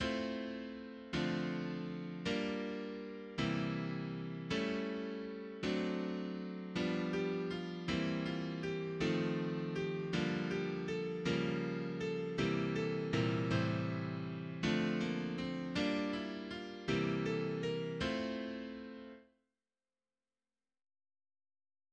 Жанр джаз